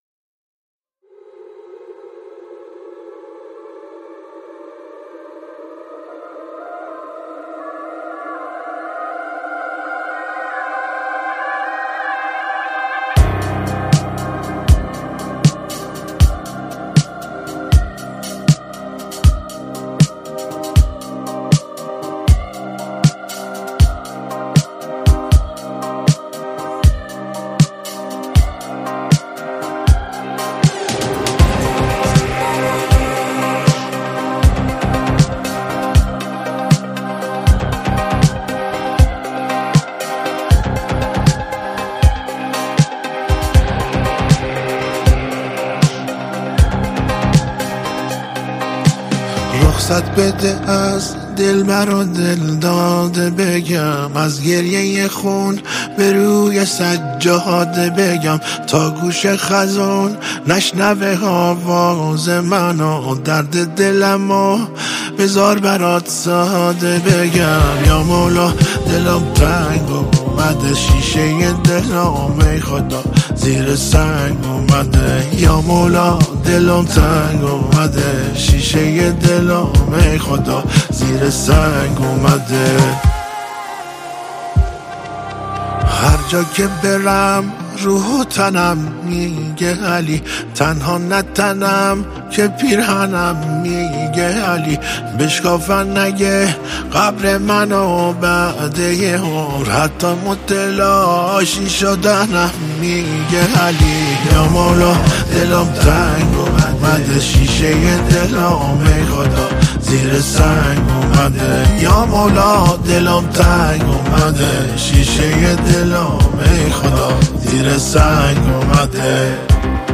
نوازنده گیتار
موسیقی آیینی و مذهبی